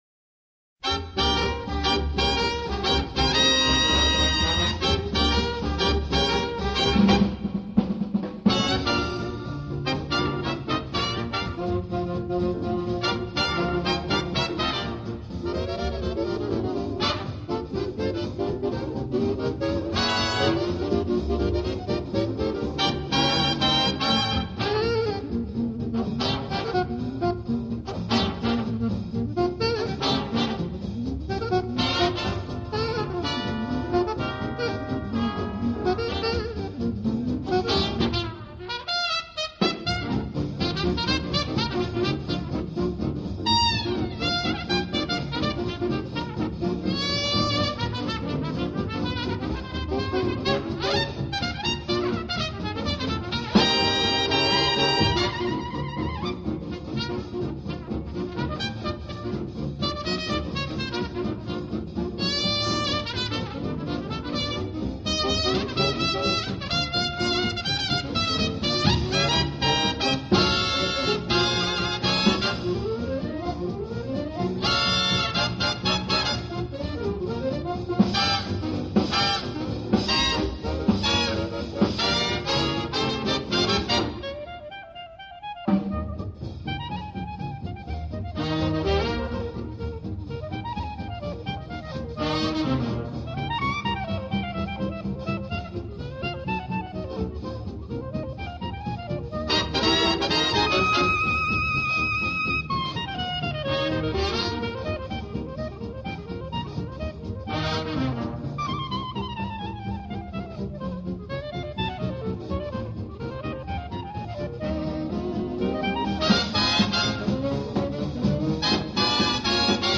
【爵士单簧管】